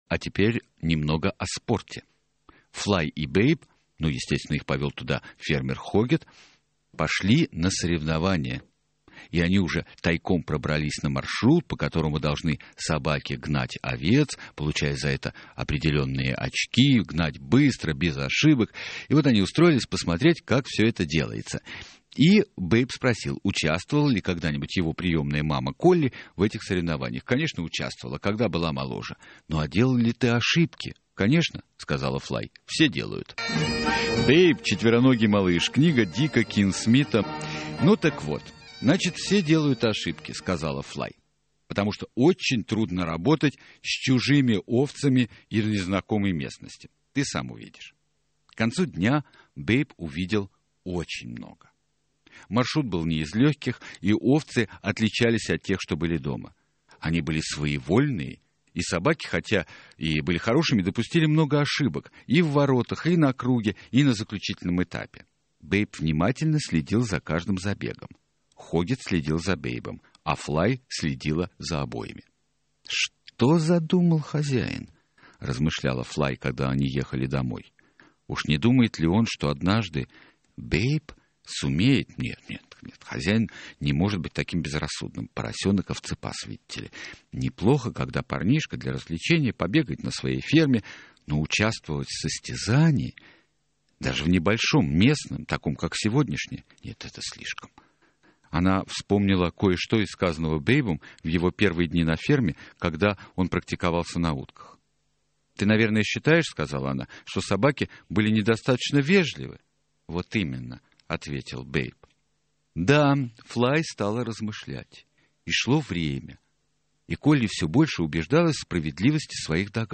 Радиоспектакль "Поросенок Бейб"